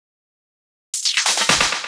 Fill 128 BPM (33).wav